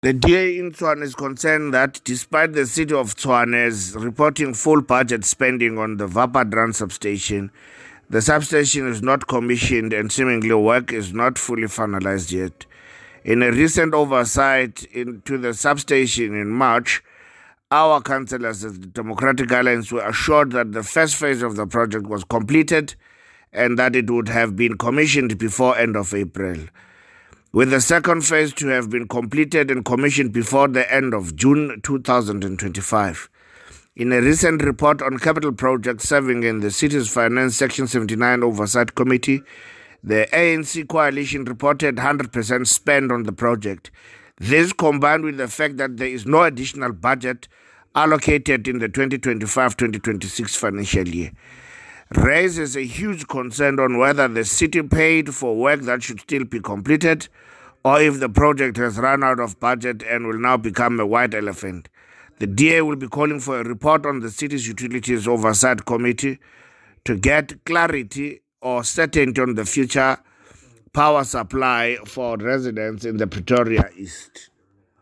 English soundbite by Cllr Themba Fosi